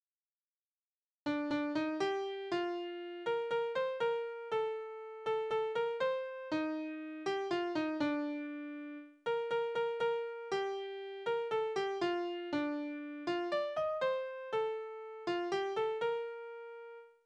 Liebeslieder: Fernsein ist kein Auseinandergehen
Tonart: B-Dur
Taktart: 4/4
Tonumfang: kleine None
Besetzung: vokal